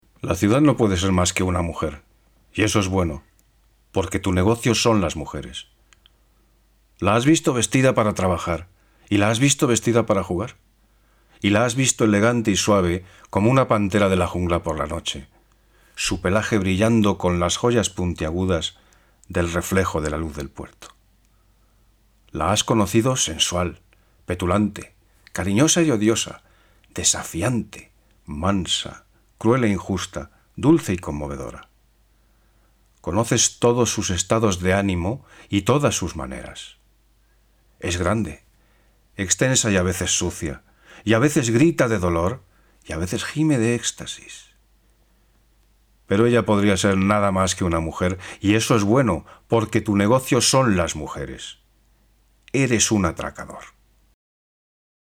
A mature and authoritative, reliable and cordial voice.
Audiolibro (El Atracador de Mujeres, Ed McBain).
Castelanian
Middle Aged